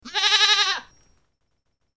oveja.mp3